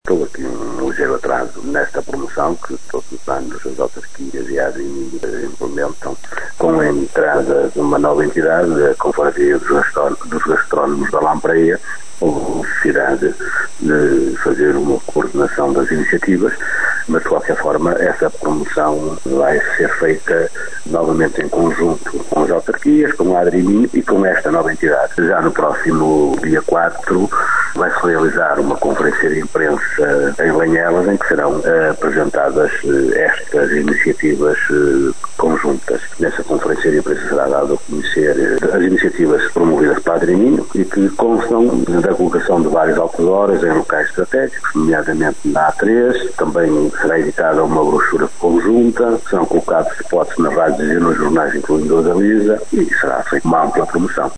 É o que garante o presidente da Câmara de Vila Nova de Cerveira. Fernando Nogueira explica que o atraso na promoção ficou a dever-se à entrada em cena da confraria dos gastrónomos da lampreia.
fernando-nogueira-apresentacao-lampreia-rio-minho.mp3